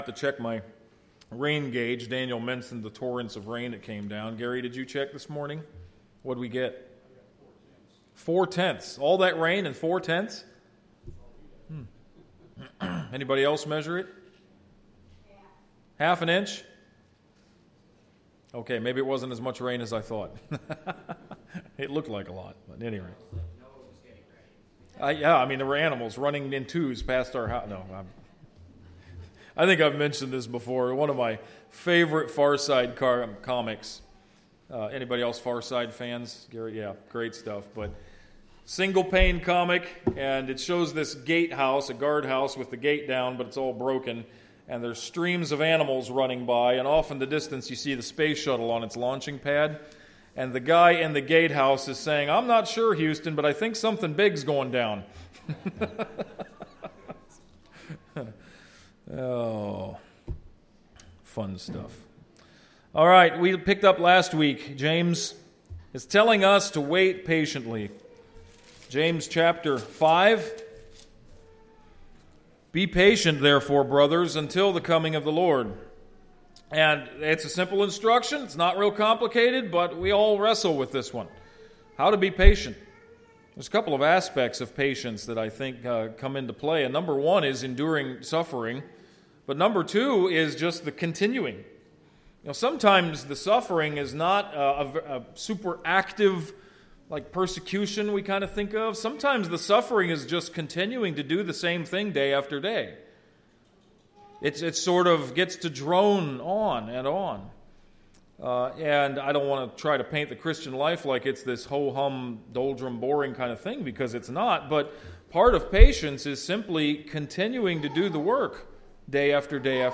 Mendota Bible Church - Audio Sermons